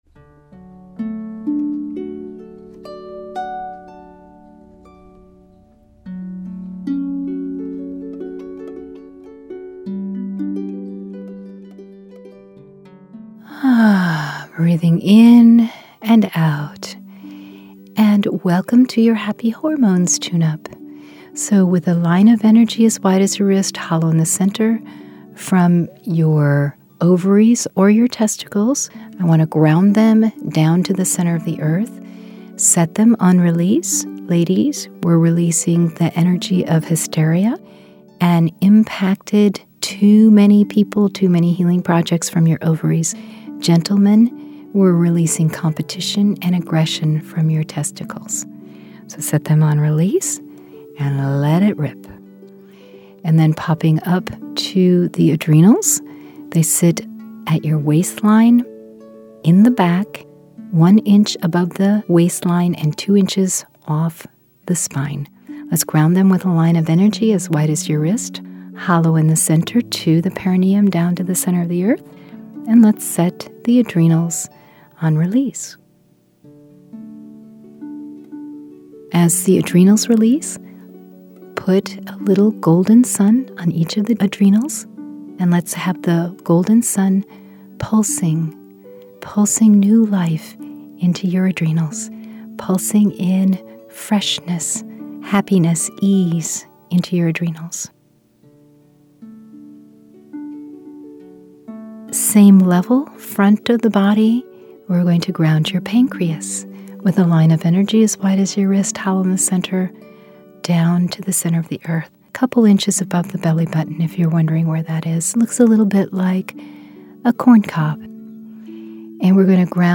Q5 Meditations